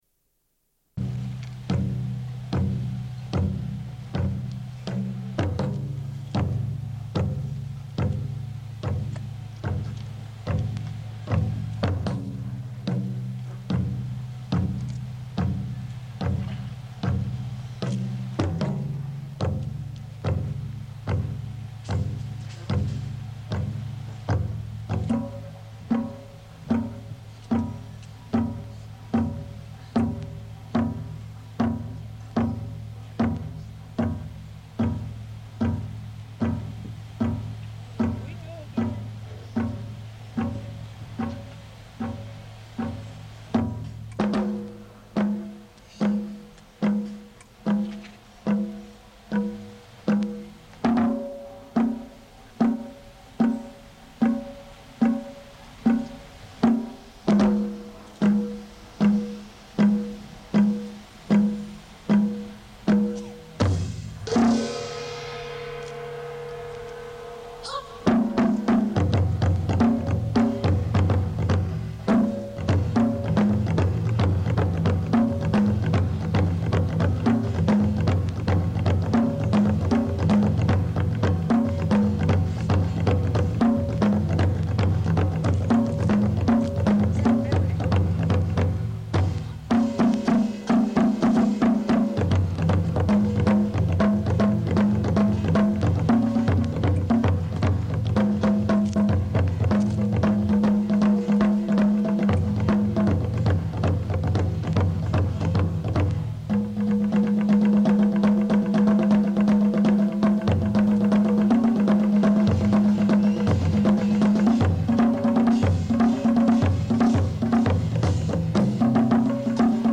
Une cassette audio, face A31:32
Émission avec une invitée revenant du Japon qui raconte son voyage.